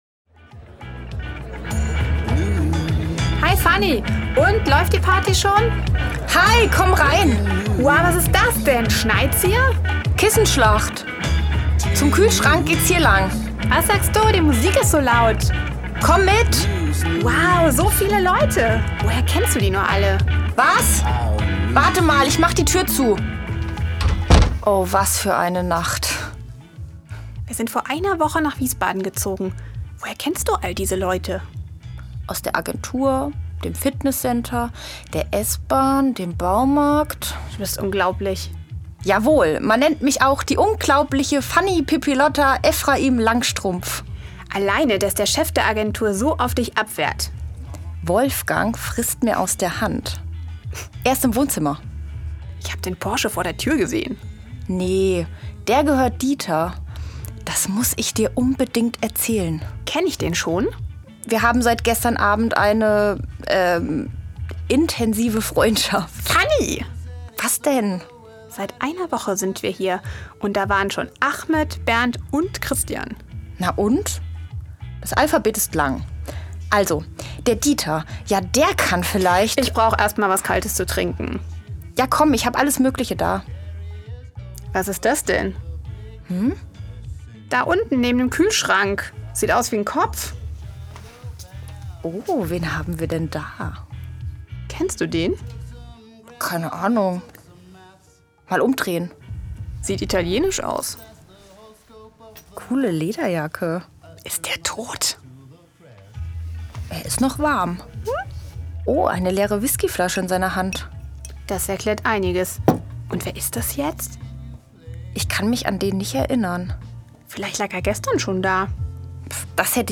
Klarinette
Zentralstudio Mainz